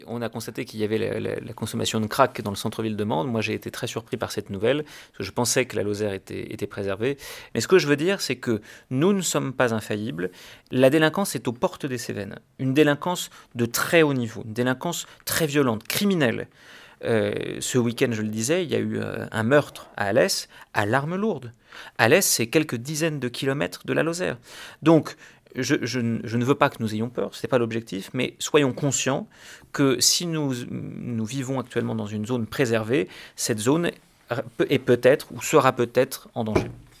La rentrée solennelle du tribunal judiciaire de Mende a eu lieu hier matin dans la grande salle d’audience.
Les escroqueries numériques progressent, tout comme la délinquance itinérante, venue de départements voisins, notamment pour des vols. À cela s’ajoute une problématique liée aux stupéfiants explique Valéry Morron, procureur de la République.